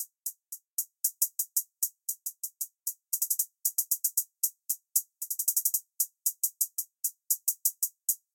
描述：hat_hat_hat_hat_hat_hat_hat_hat_hat_hat_hat_hat_
标签： 帽子
声道立体声